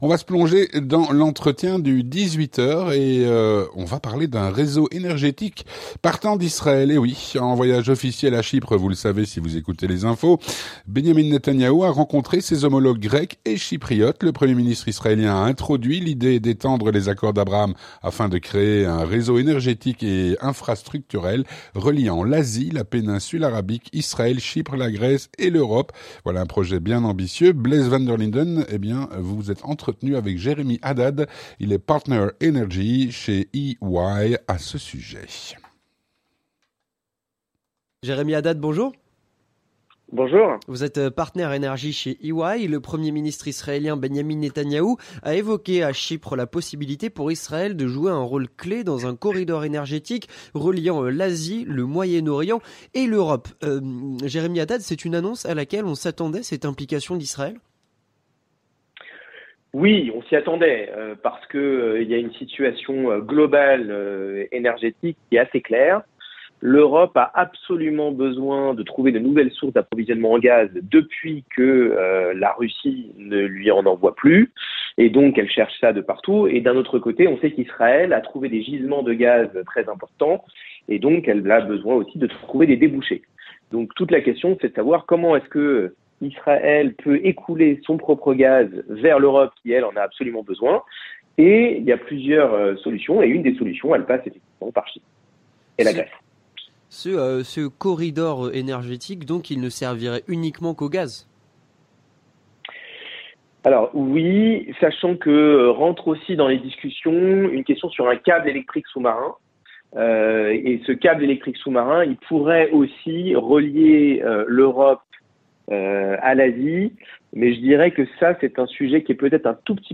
Lentretien du 18H